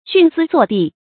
徇私作弊 xùn sī zuò bì
徇私作弊发音
成语注音ㄒㄩㄣˋ ㄙㄧ ㄗㄨㄛˋ ㄅㄧˋ